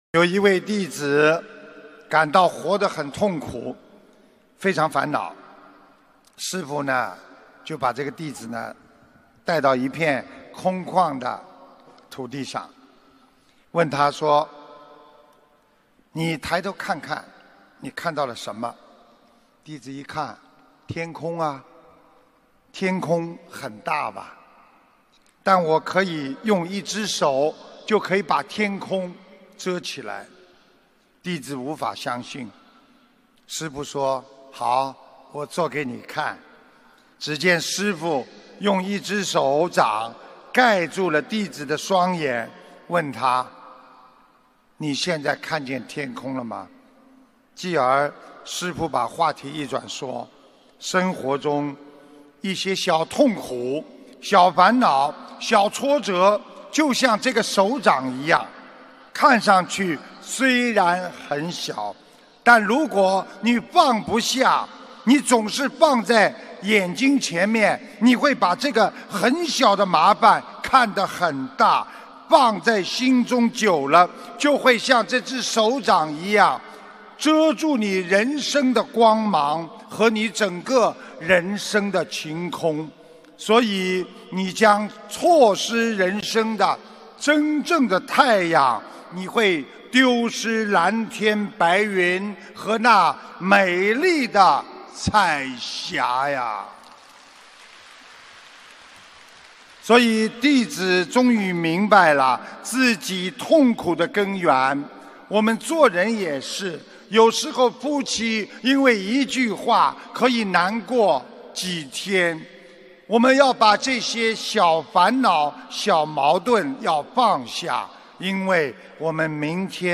音频：勿让生活的小烦恼遮住人生的太阳·师父讲小故事大道理